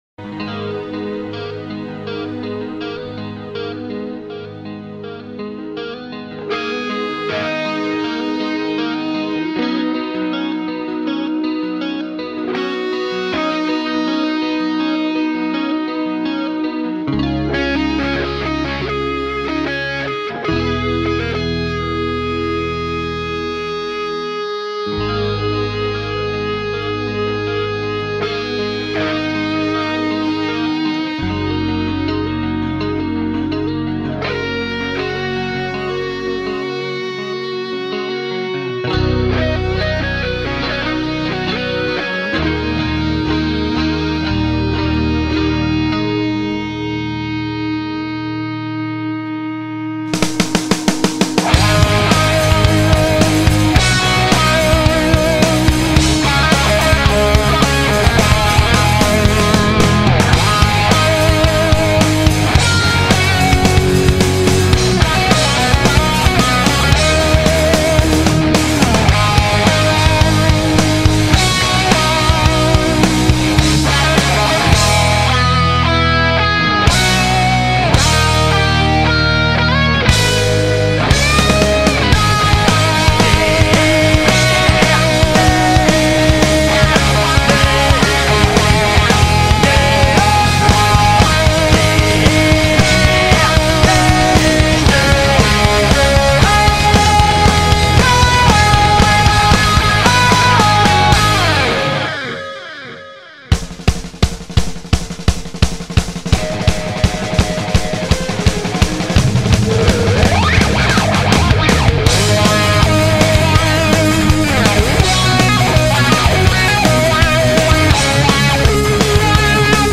Heavy Metal, Symphonic Metal, Classical